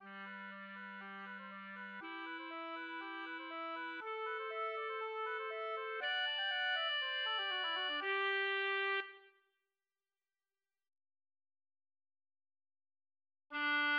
<< \new Staff << \relative c'' { \time 4/4 \key g \major \set Staff.midiInstrument="oboe" \tempo 4=120 g8\ppp\< b8 d8 b8 g8 b16( c16) d8 b8 | g8 b16( c16) e8 b8 g8 b16( c16) e8 b8 | a8 b16( c16) e8 d16( c16) a8 b16( c16) e8 c8 | fis8 a16( g16) fis8 e16( d16) c8 a16( g16 fis16 e16 fis16 d16) | g2\fff r2 | r1 | r2. d4 | } >> \new Staff << \relative c' { \time 4/4 \key g \major \set Staff.midiInstrument="clarinet" g1\ppp\< | e'1 | a1 | d1 | g,2\fff r2 | r1 | r2. d4 | } >> >>